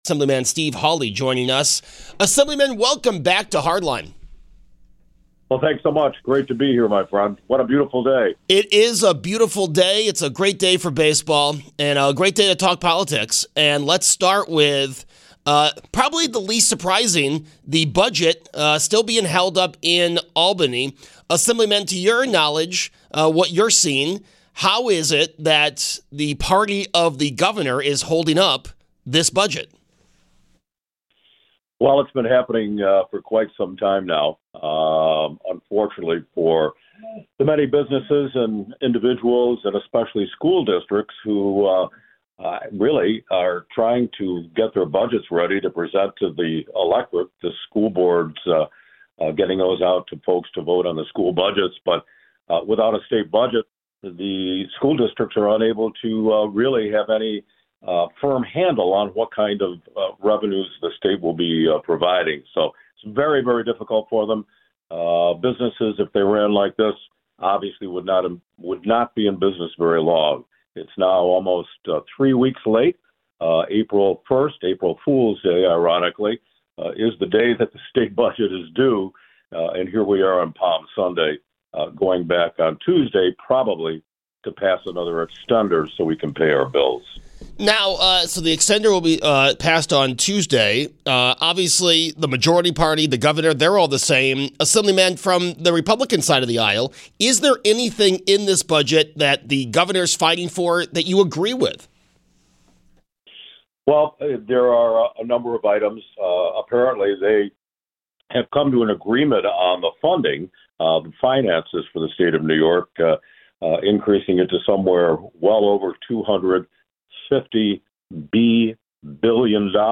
Assemblyman Steve Hawley joins the show to discuss the Budget delay in Albany, and other state issues.